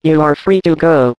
scanner
spch_proceed2.ogg